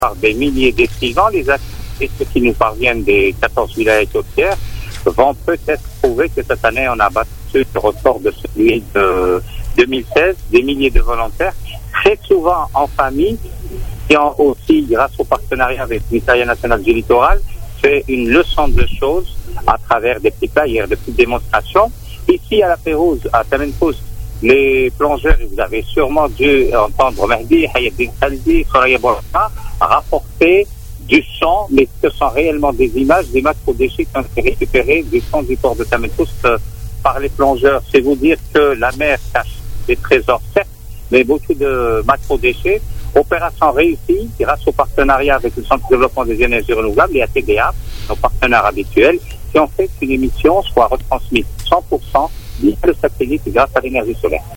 Fatma-Zohra Zerouati, ministre de l'Environnement et des énergies renouvelables, au micro de la Radio chaine 3